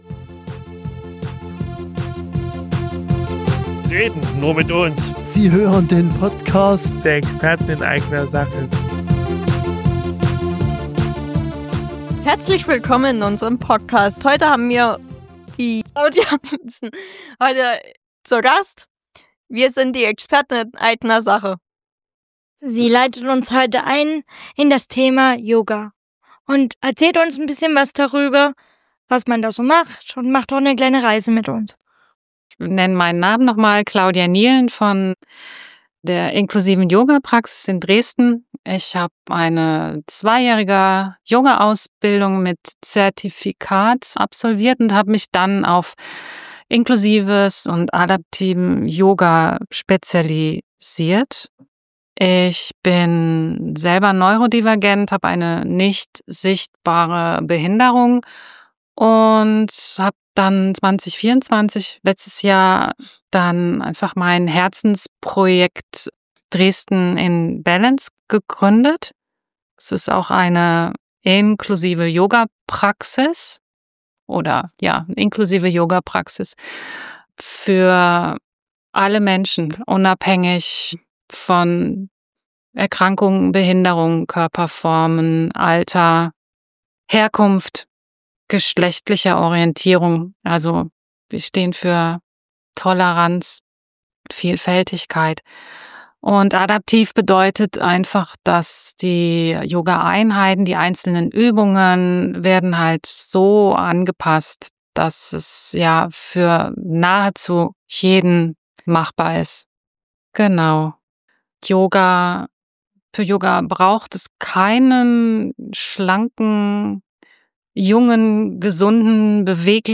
Wir machen mit ihr eine kleine Yoga-Reise. Wir sprechen darüber, was Yoga mit Selbst-Vertretung und Politik zu tun hat.